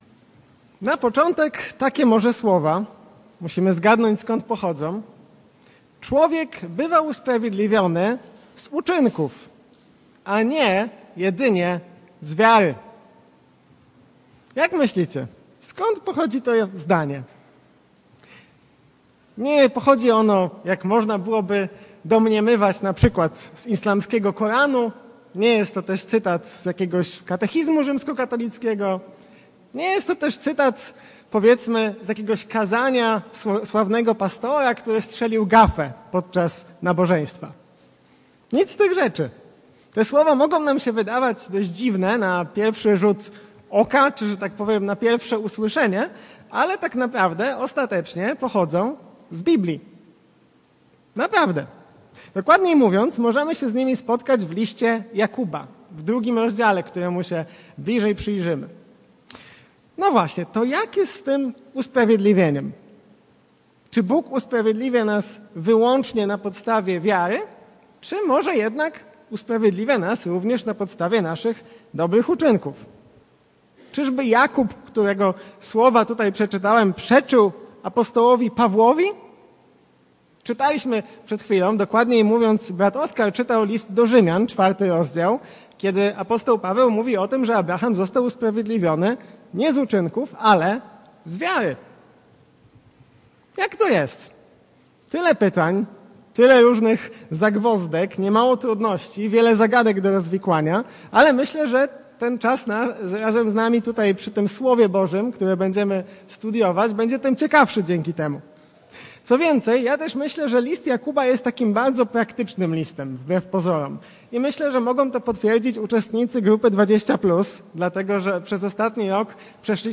Kazanie
wygłoszone na nabożeństwie w niedzielę 25 sierpnia 2024 r.